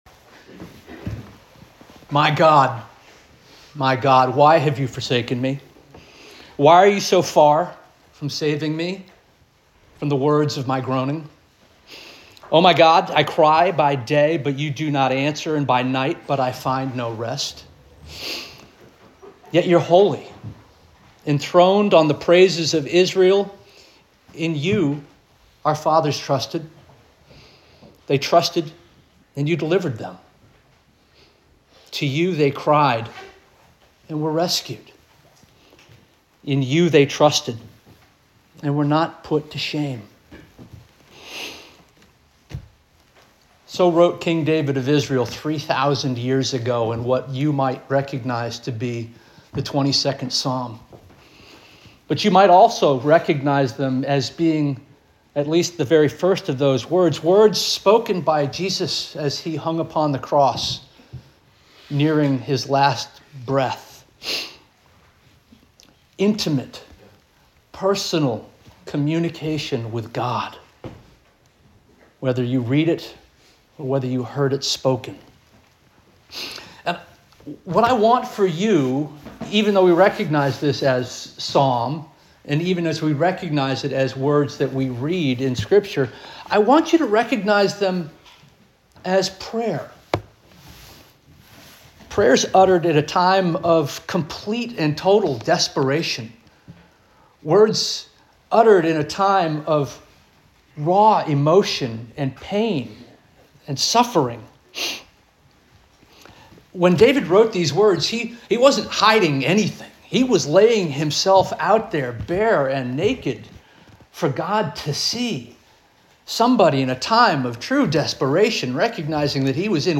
January 4 2026 Sermon - First Union African Baptist Church